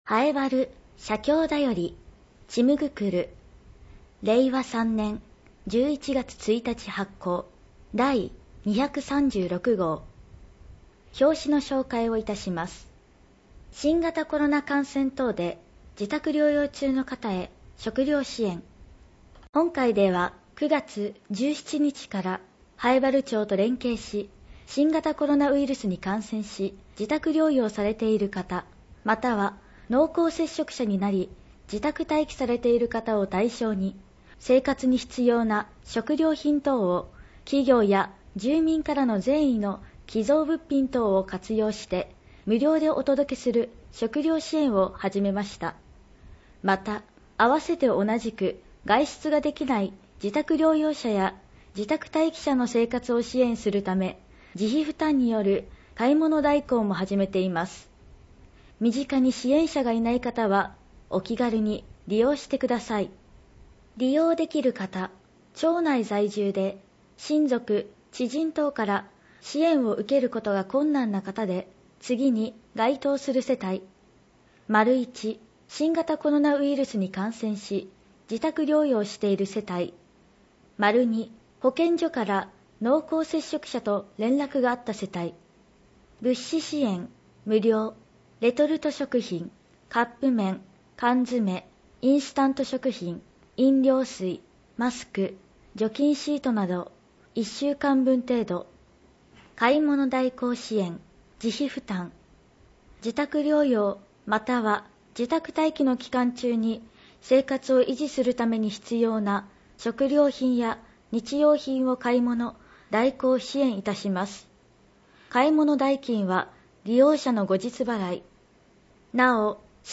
以下は音訳ファイルです